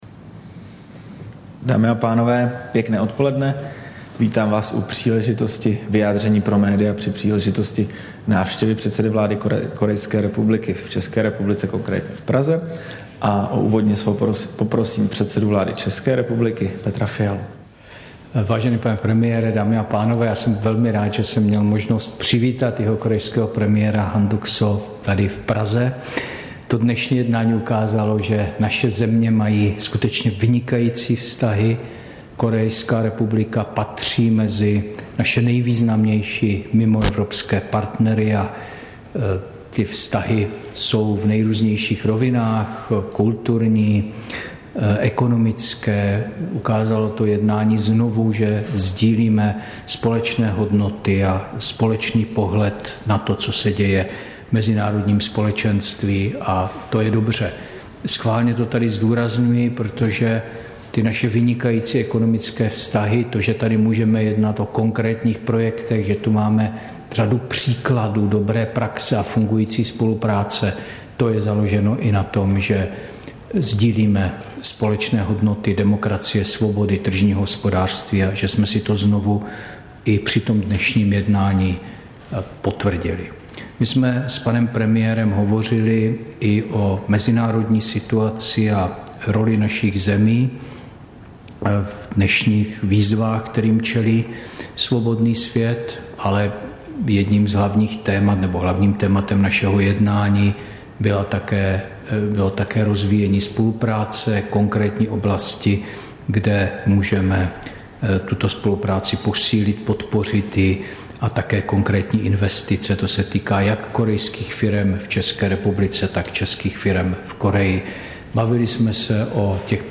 Tisková konference po jednání s předsedou jihokorejské vlády Han Duck Soo, 12. září 2023